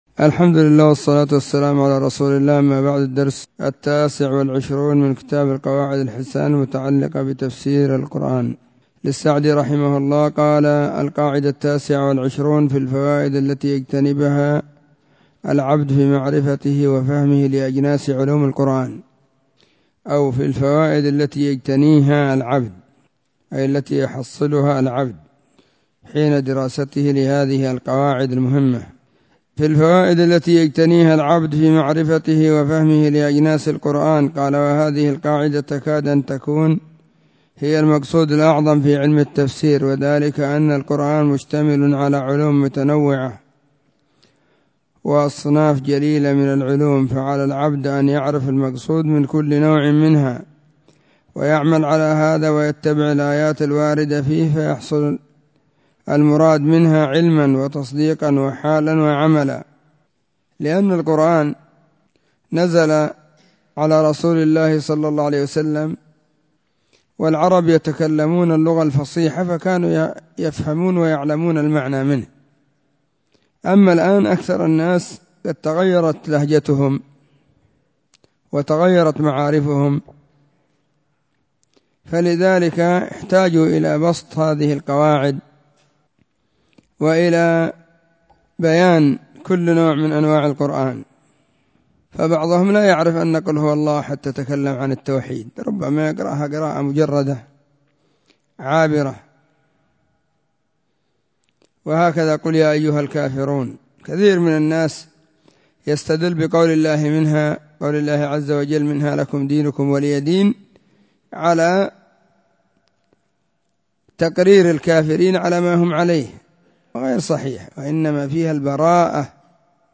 القواعد الحسان المتعلقة بتفسير القرآن الدرس29
🕐 [بعد صلاة الظهر في كل يوم الخميس]